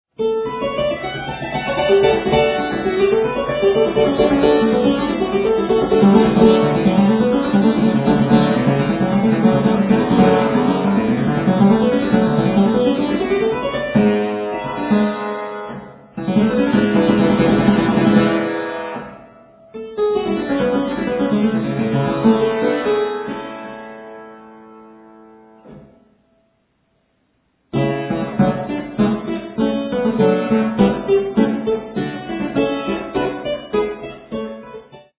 performed on clavichord